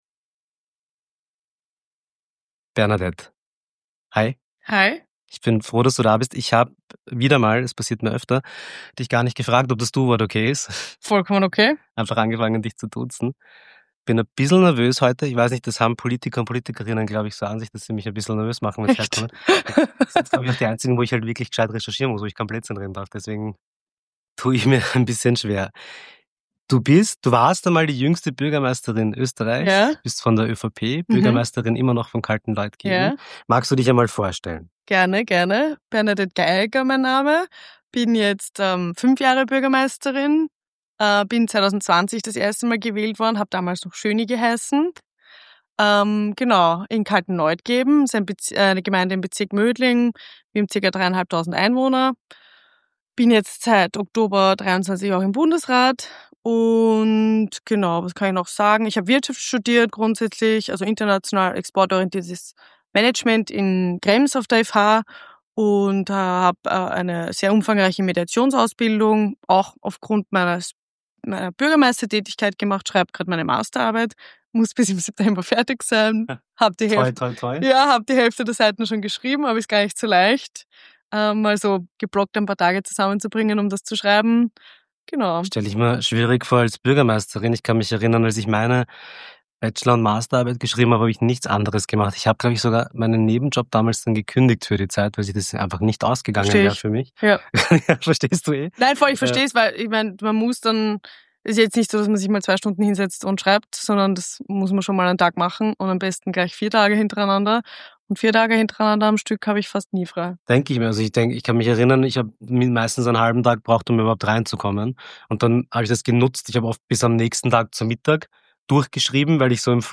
In dieser Episode von "Motalks" begrüßen wir Bernadette Geieregger – die Bürgermeisterin von Kaltenleutgeben, Bundesrätin und Mediatorin, die trotz ihres jungen Alters beeindruckende politische Akzente setzt.